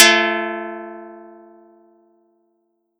Audacity_pluck_9_14.wav